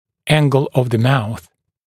[‘æŋgl əv ðə mauθ][‘энгл ов зэ маус]угол рта